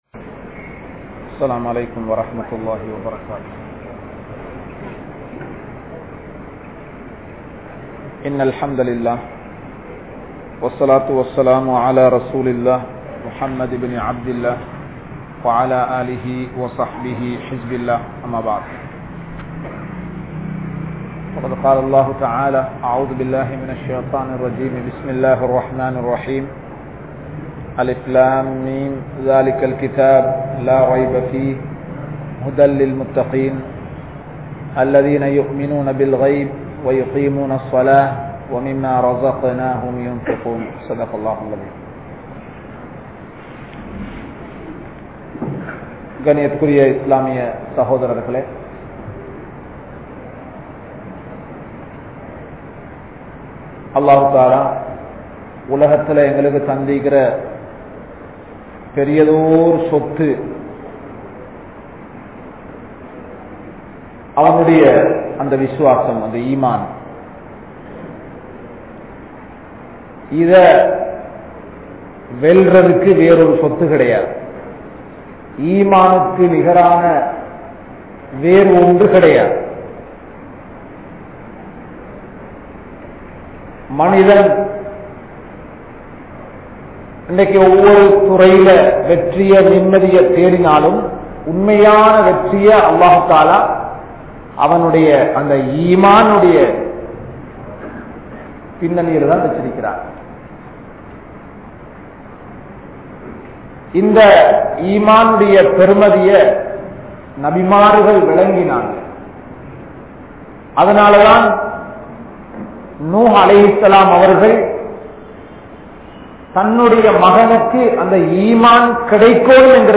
Allah`vin Sakthi | Audio Bayans | All Ceylon Muslim Youth Community | Addalaichenai